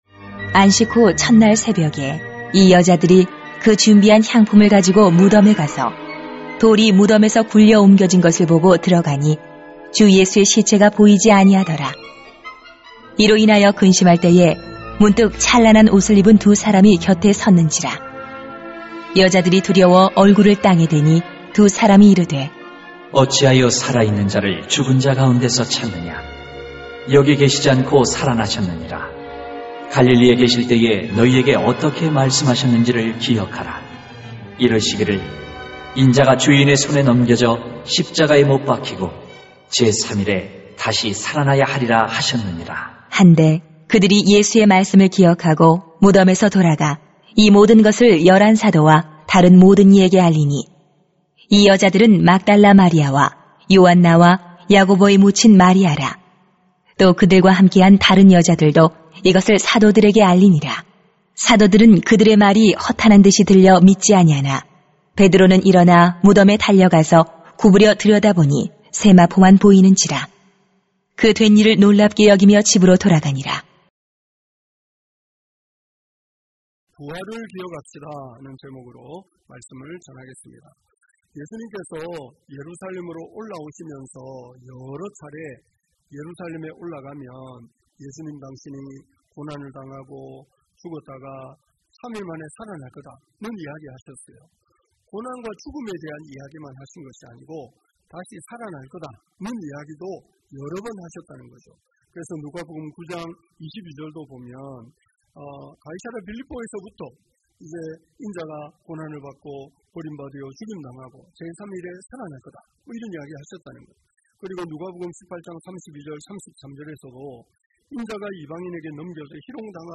[눅 24:1-12] 부활을 기억합시다 > 주일 예배 | 전주제자교회